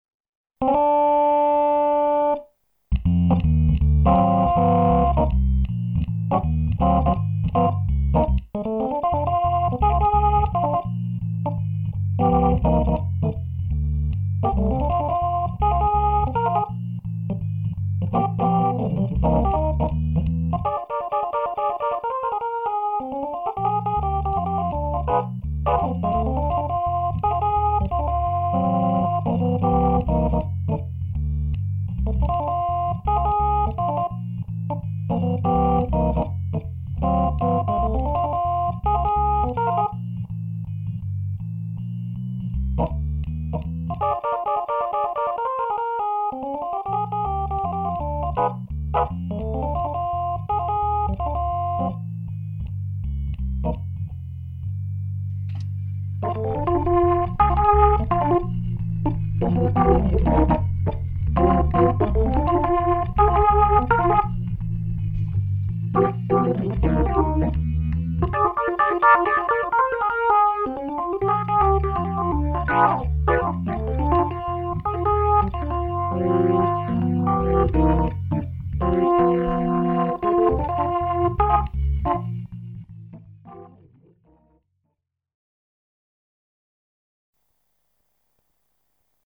I`ve been working on a big sample + prog/combi set of Hammond sounds lately.
b3shuffle_processed.mp3